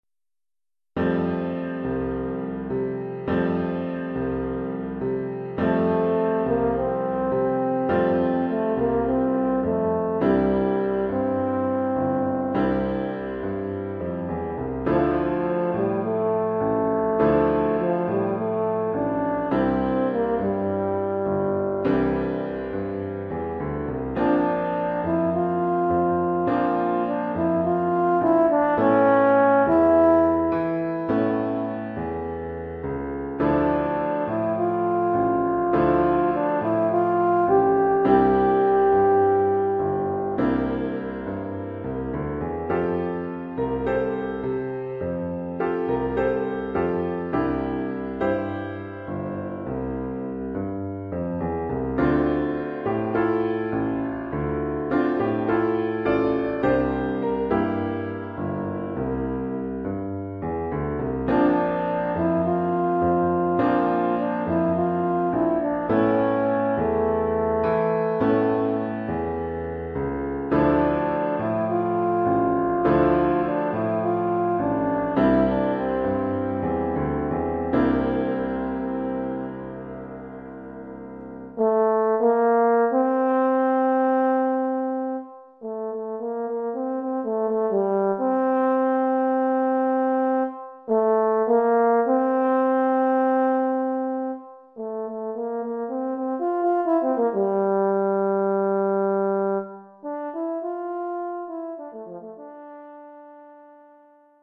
Oeuvre pour saxhorn alto mib et piano.